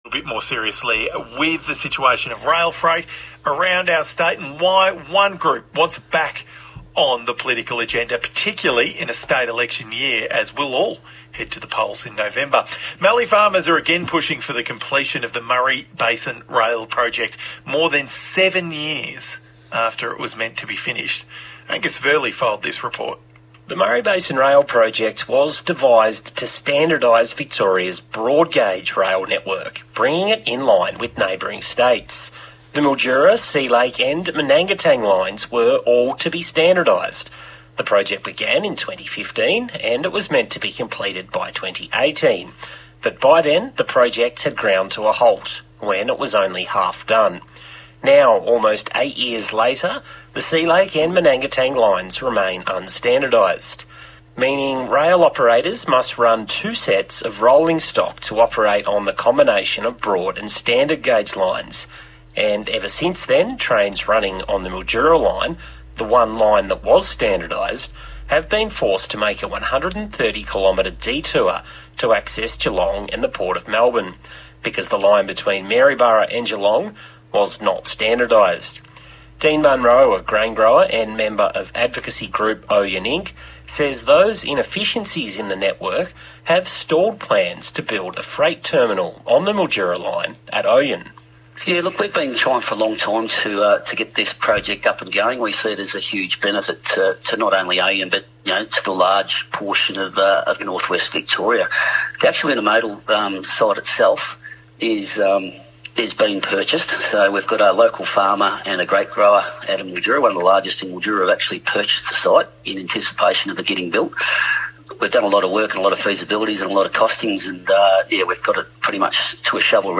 Extracts from two interviews with the ABC Radio in April '26 including Ouyen Inc & VFF